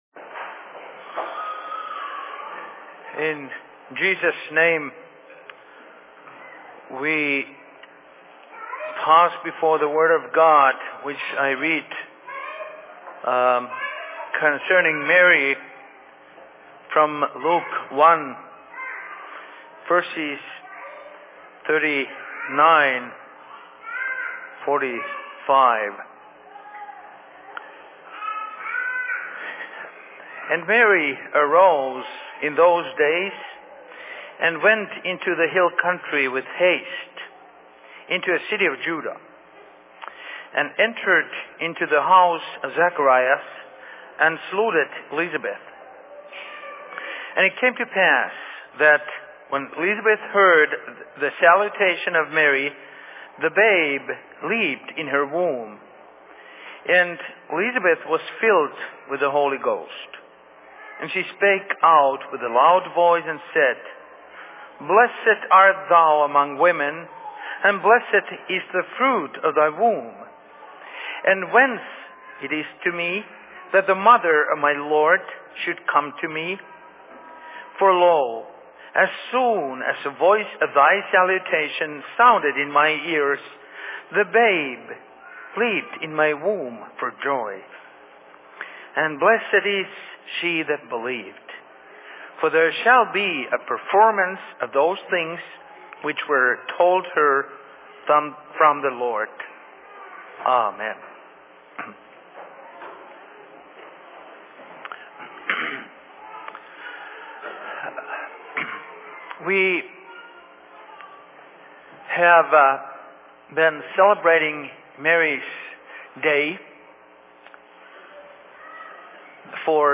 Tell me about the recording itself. Location: LLC Rockford